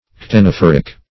ctenophoric - definition of ctenophoric - synonyms, pronunciation, spelling from Free Dictionary
Search Result for " ctenophoric" : The Collaborative International Dictionary of English v.0.48: Ctenophoric \Cten`o*phor"ic\ (t?n`?-f?r"?k), Ctenophorous \Cte*noph"o*rous\ (t?-n?f"?-r?s), a. (Zool.)